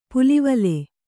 ♪ pulivale